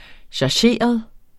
Udtale [ ɕɑˈɕeˀʌð ]